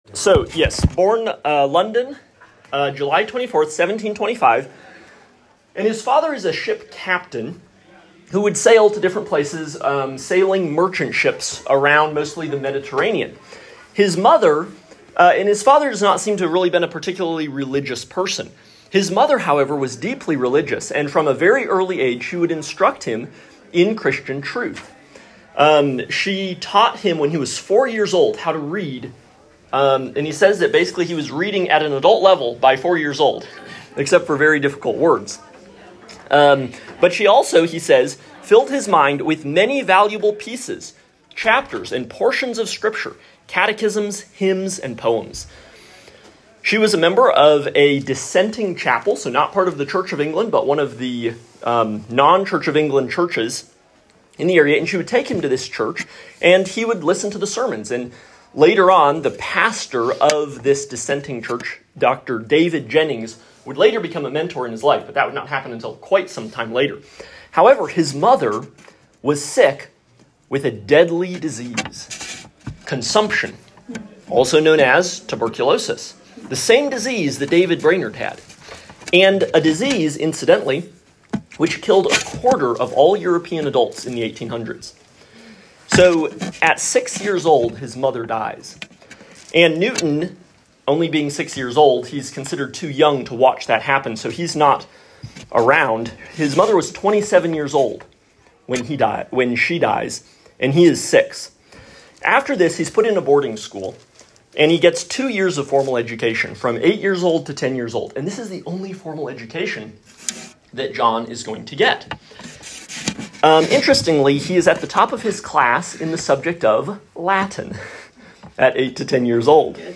Lecture 5 – John Newton
Lecture-5-John-Newton.m4a